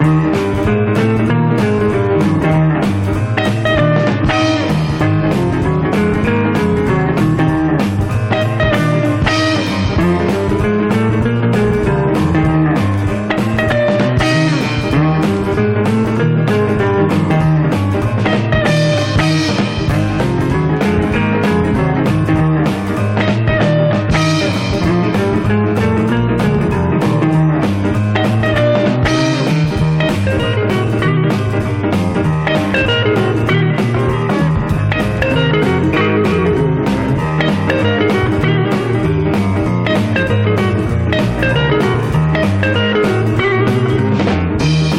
Rock, Pop　France　12inchレコード　33rpm　Stereo